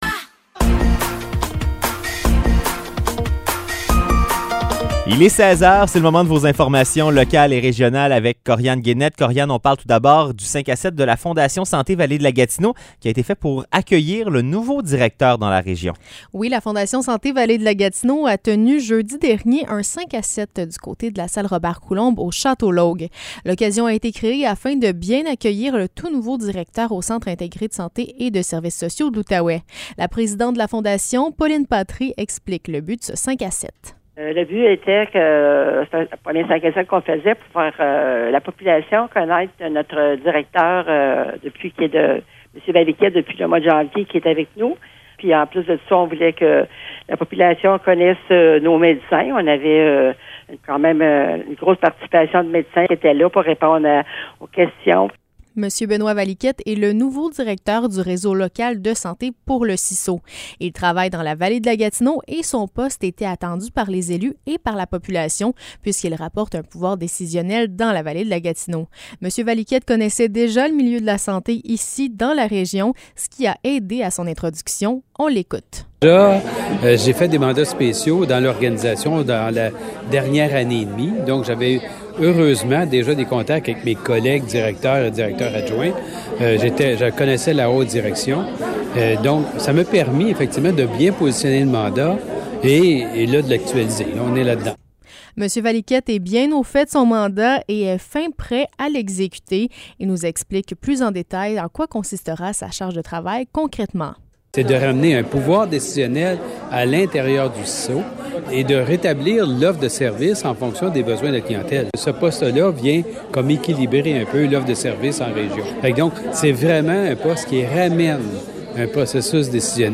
Nouvelles locales - 27 mars 2023 - 16 h